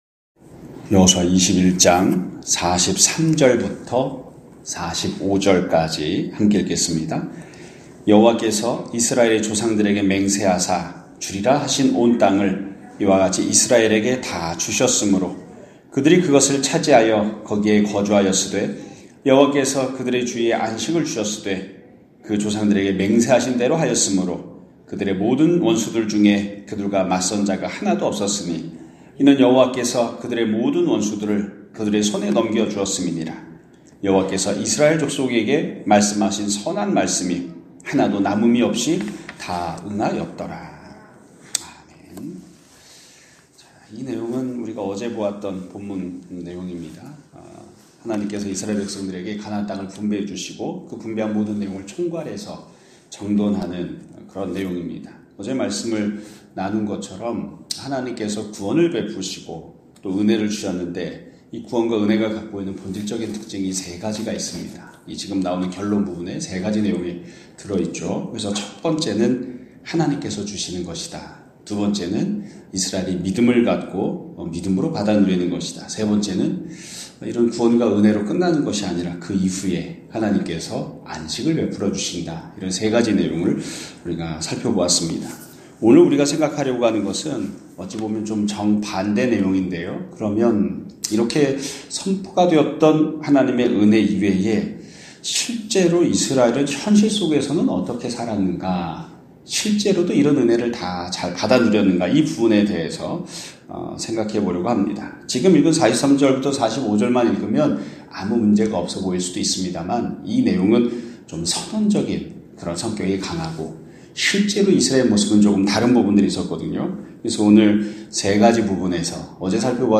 2025년 2월 4일(화 요일) <아침예배> 설교입니다.